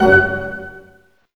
Index of /90_sSampleCDs/Roland LCDP08 Symphony Orchestra/HIT_Dynamic Orch/HIT_Staccato Oct